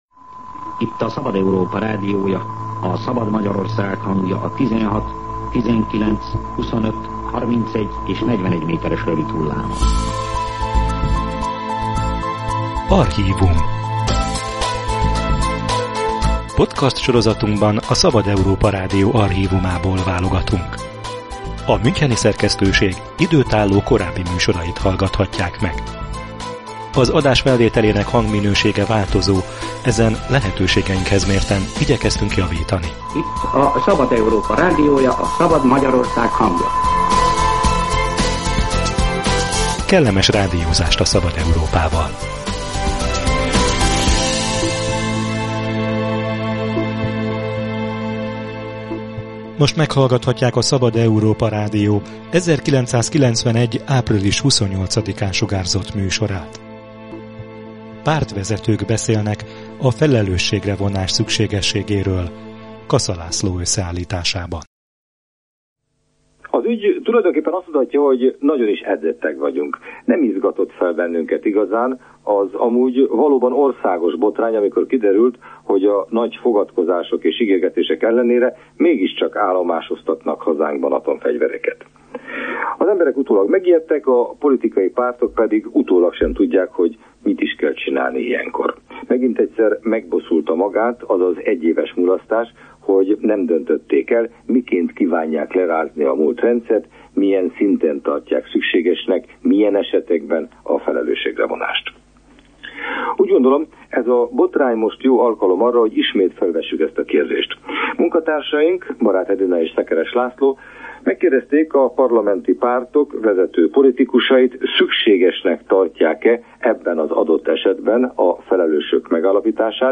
Pártvezetők beszélnek a felelősségre vonás fontosságáról – műsor a Szabad Európa Rádió archívumából